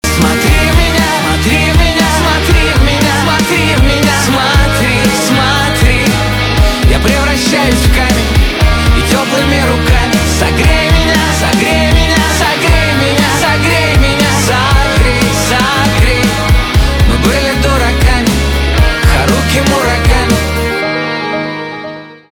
русский рок
печальные
чувственные , гитара , барабаны , пианино